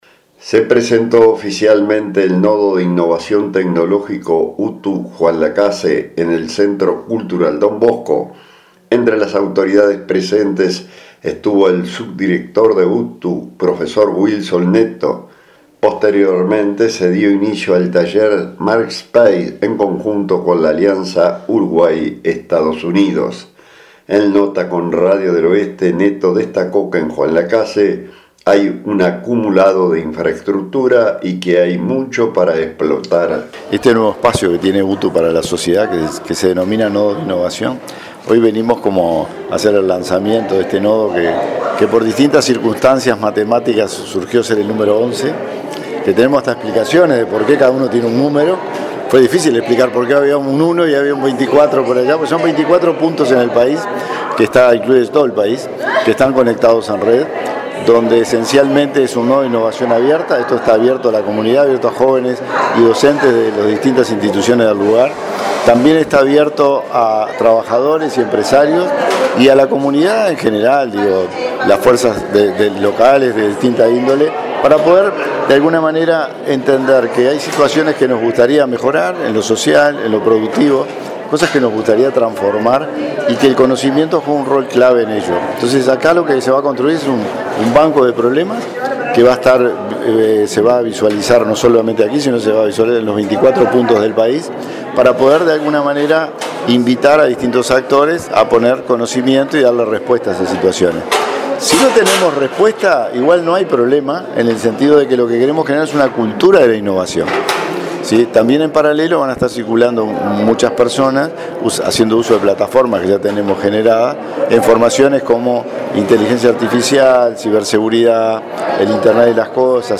En nota con Radio del Oeste Netto destacó que en Juan Lacaze hay un acumulado de infraestructura y que hay mucho para explotar.